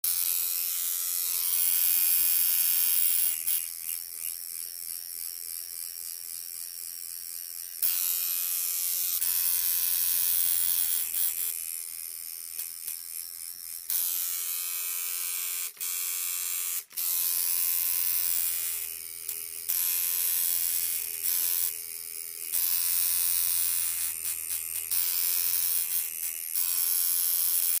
tattoo-machine-sfx.mp3